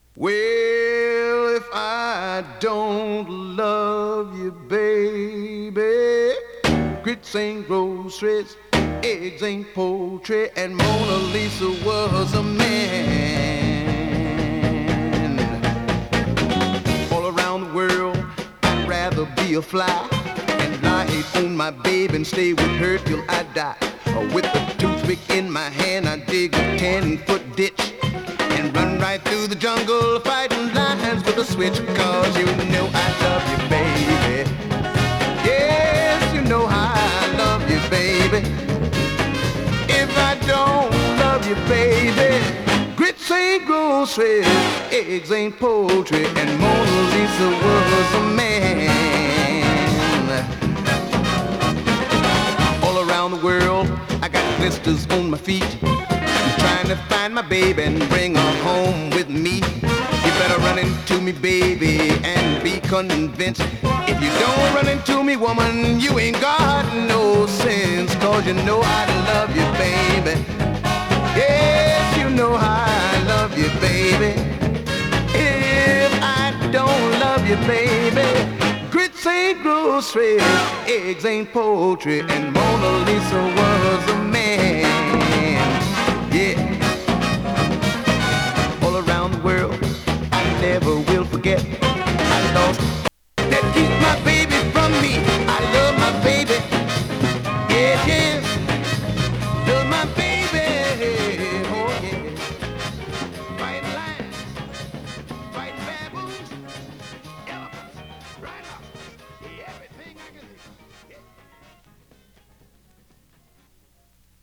＊音の薄い部分で時おり軽いチリ/パチ・ノイズ。